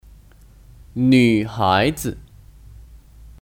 女孩儿 Nǚháizi (Kata benda): Anak perempuan那个女孩子很漂亮 Nàge nǚ háizi hěn piàoliang (Anak perempuan itu sangat cantik)